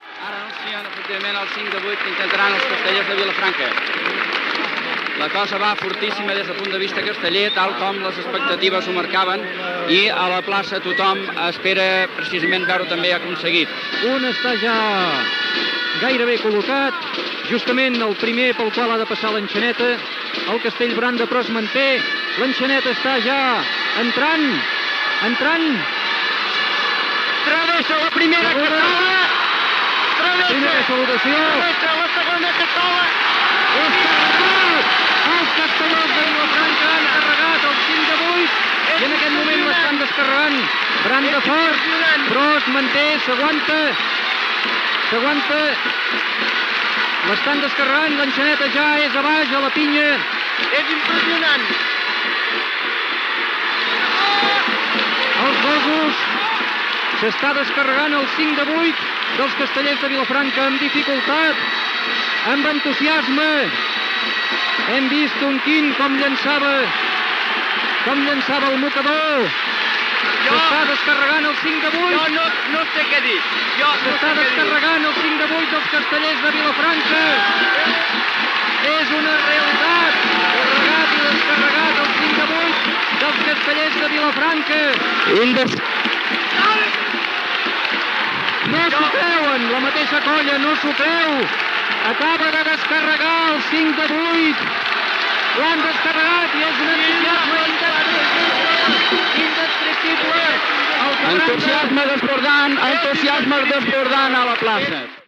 Narració del primer castell 5 de 8 fets pels Castellers de Vilafranca a la Diada de Sant Fèlix
Ràdio Vilafranca va ser la primera ràdio en fer transmissions en directe de diades castelleres.